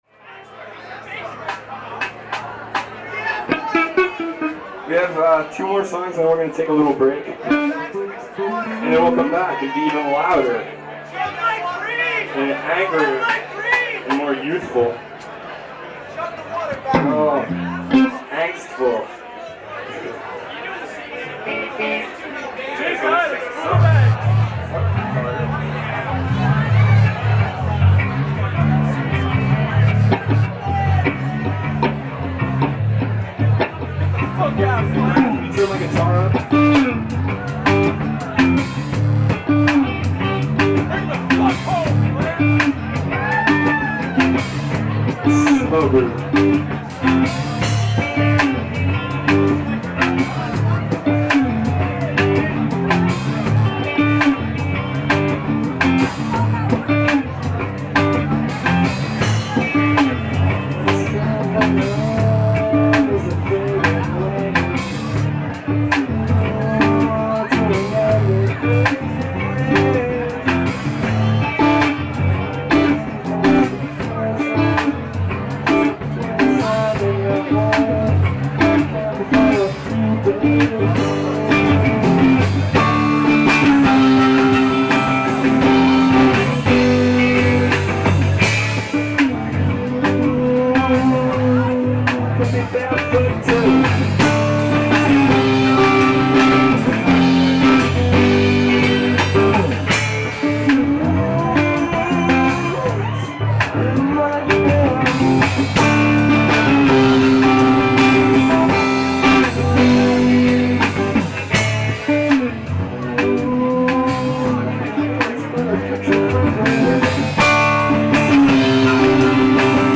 Bass
Lead Guitar, Vocals
Drums
Live at the Underground (10/13/95) [Entire Show]